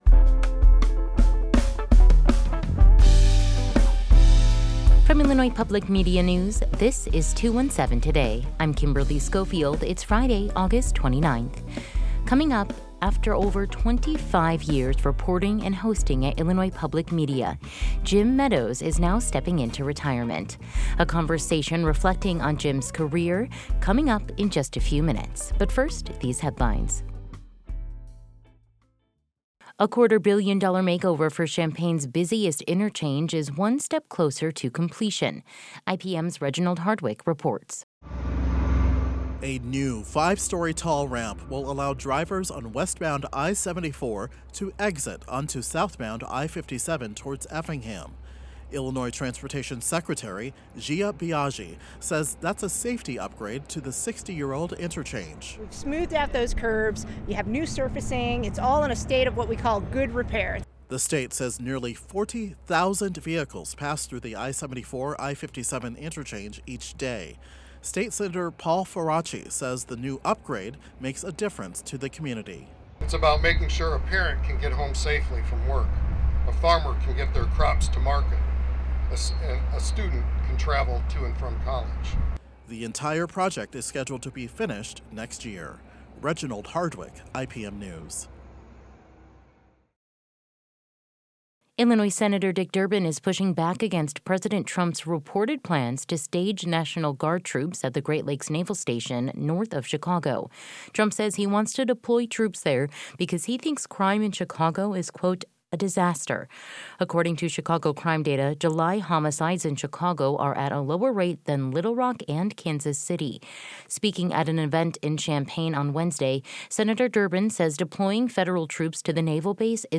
a final conversation
Today’s headlines: A quarter-billion dollar makeover for Champaign’s busiest interchange is one step closer to completion. Illinois Senator Dick Durbin is pushing back against President Trump’s reported plans to stage National Guard troops at the Great Lakes Naval Station north of Chicago. Carle Health says it’s preparing to see a lot more patients at its Urbana hospital as OSF HealthCare plans to shift some specialty services to other cities.